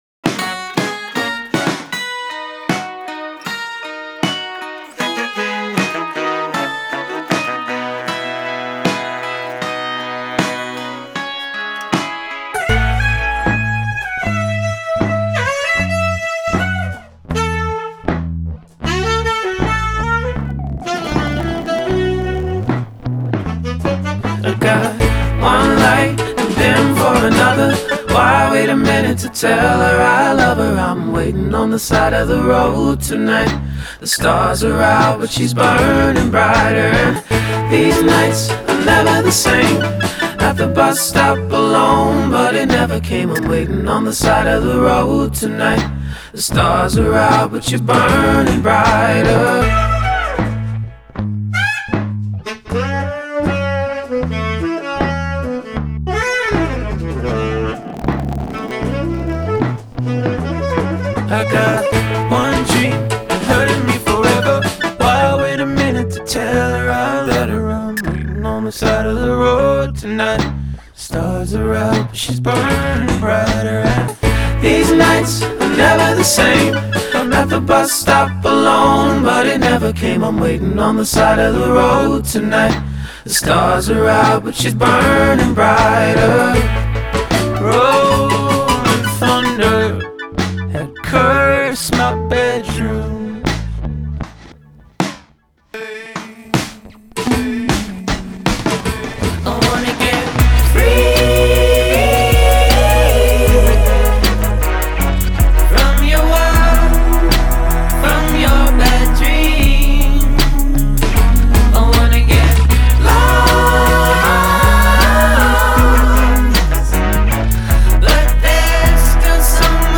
pastiche quirkiness